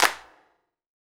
PCLAPS.wav